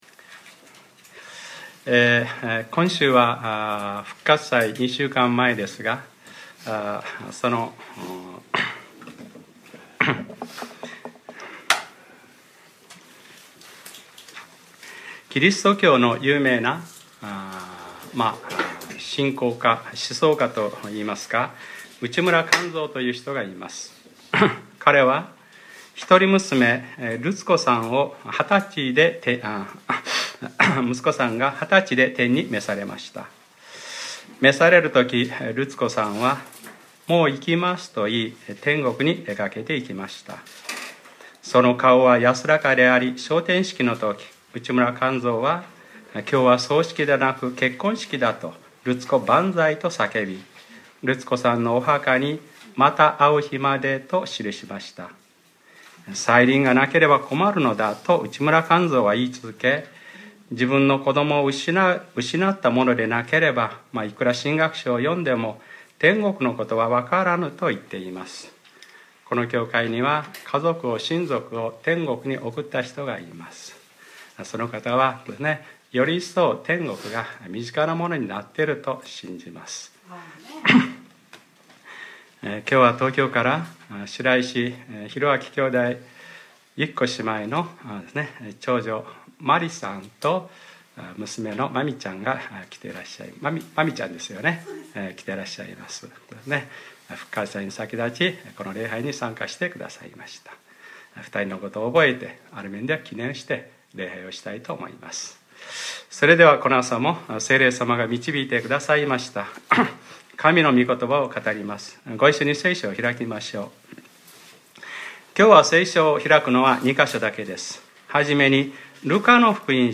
2015年03月22日）礼拝説教 『ルカｰ５７：食べて祝おうではないか』